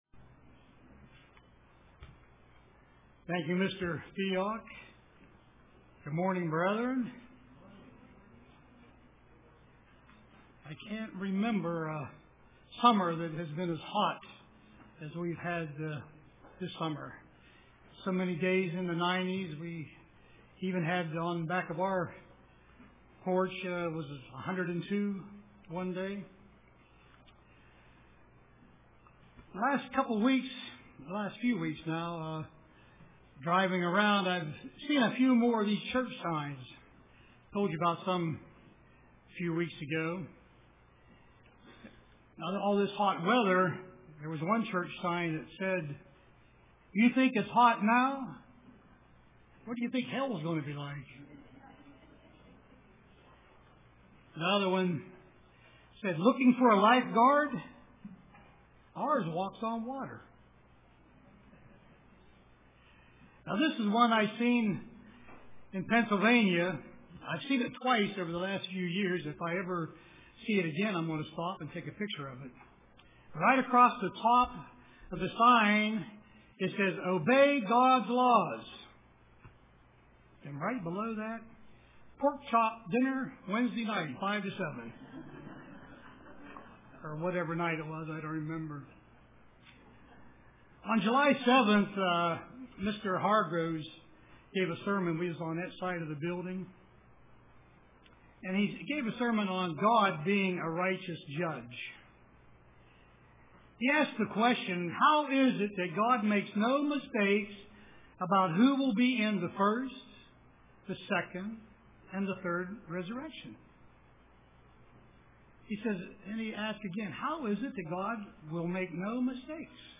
Print Why Study the Bible UCG Sermon